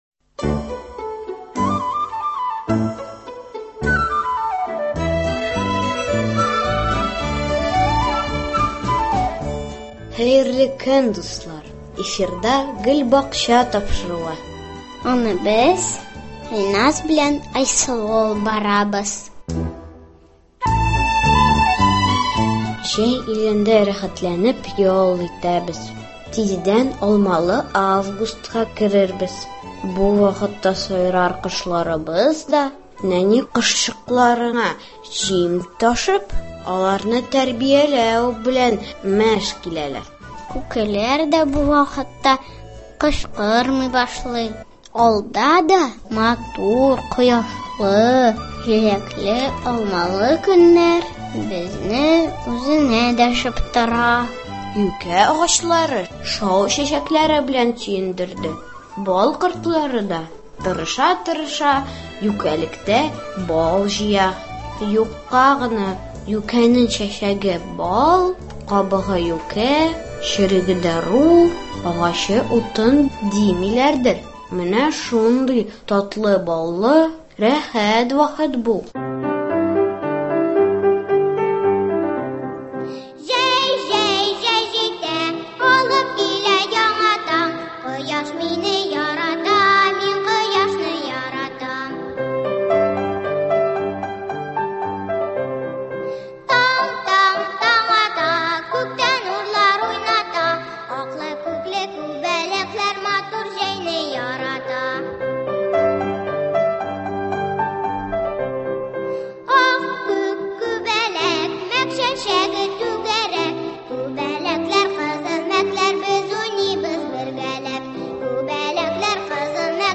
нәни дусларыбыз башкаруында шигырьләр, җырлар да тыңларбыз.